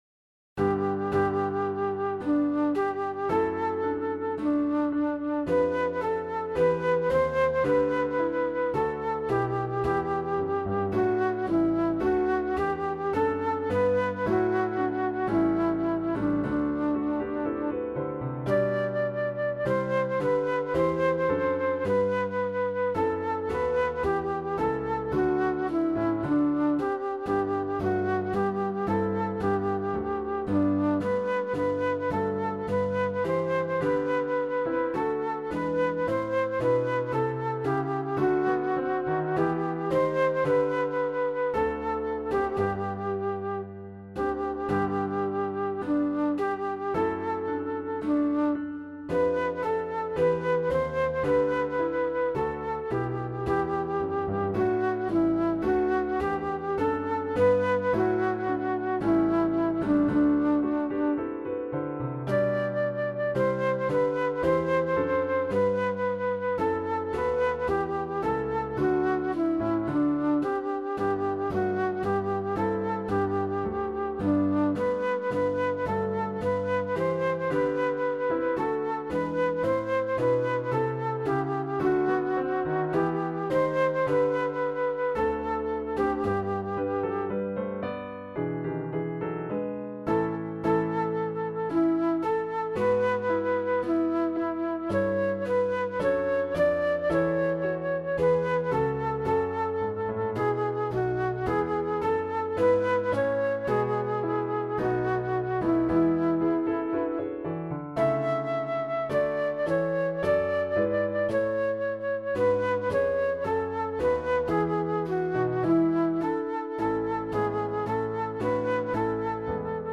• Contiene melodía de solista (flauta, violín, etc)
• Tono original: G
• Partitura está ajustada para acompañar a Coro.
Vista previa de audio